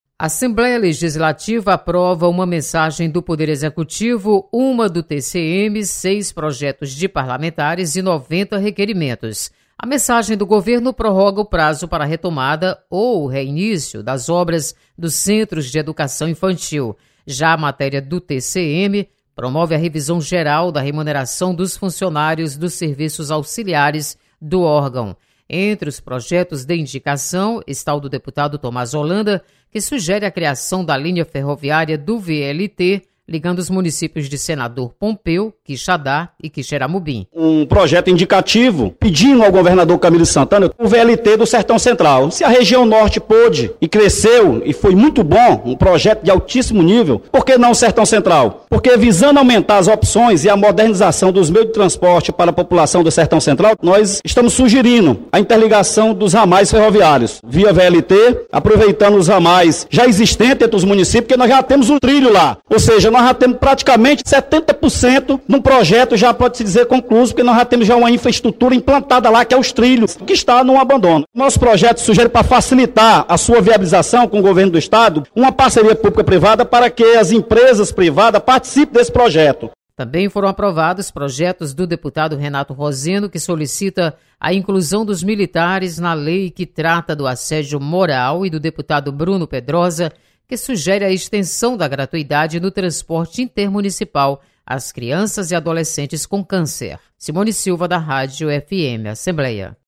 Plenário aprova prorrogação de prazo para construção dos CEIs. Repórter